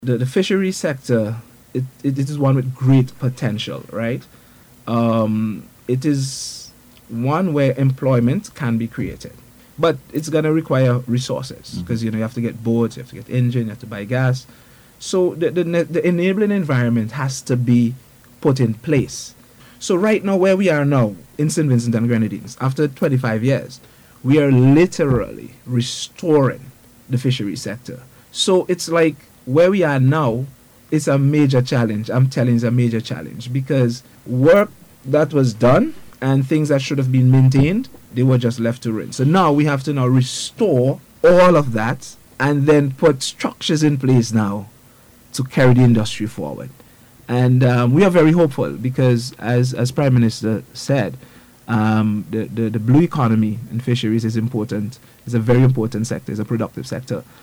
Speaking on NBC Radio yesterday, Minister Huggins said the sector is poised to provide sustainable economic growth.